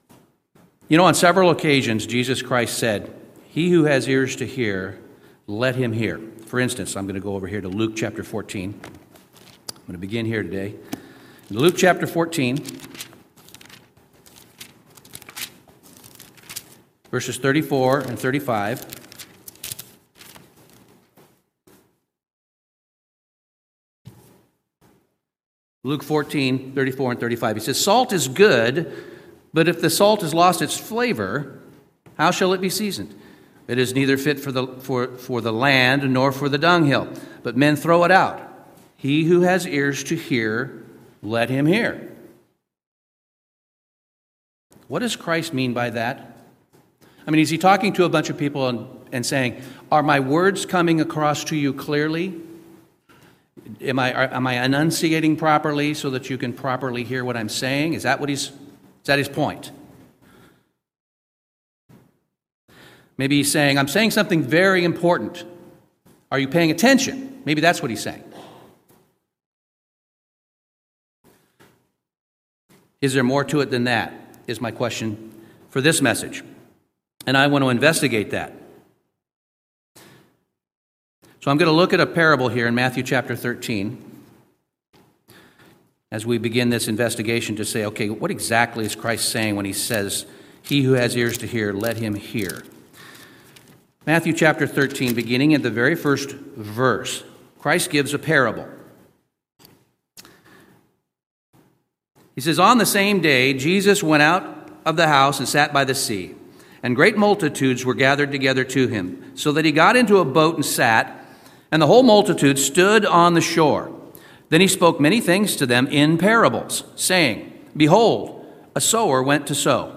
Webcast Sermons